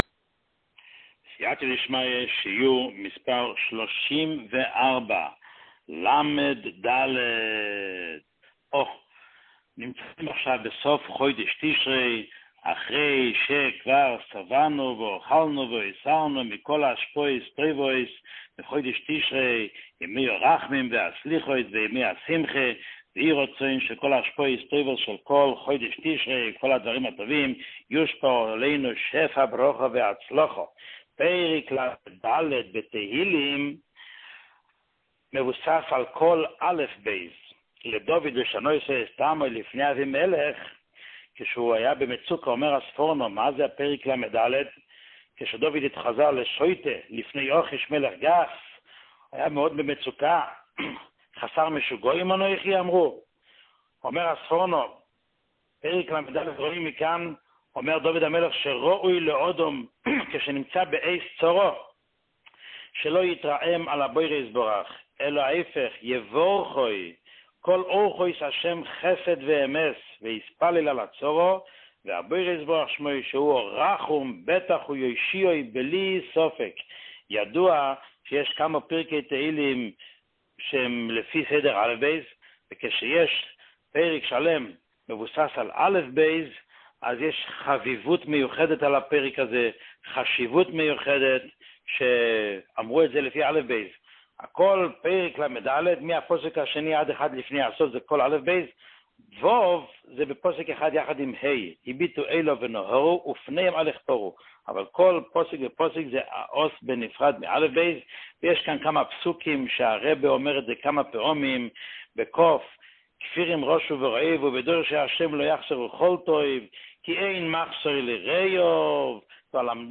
שיעורים מיוחדים
שיעור 34